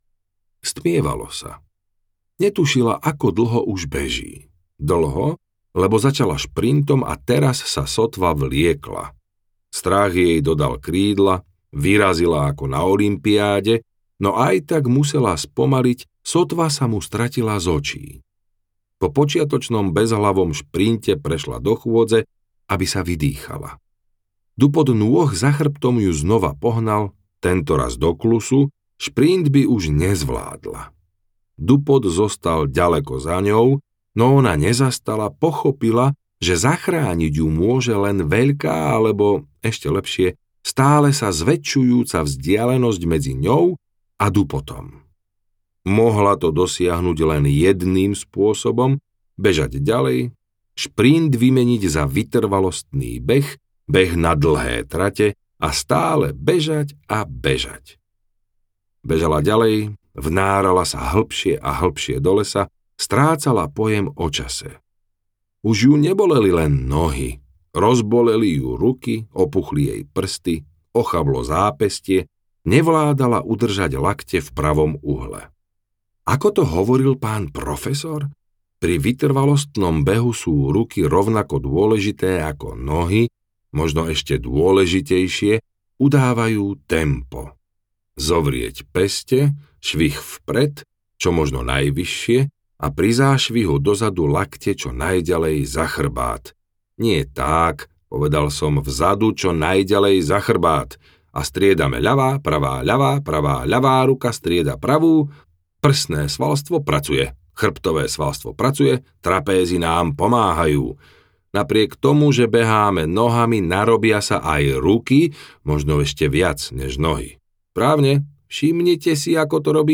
Veštica audiokniha
Ukázka z knihy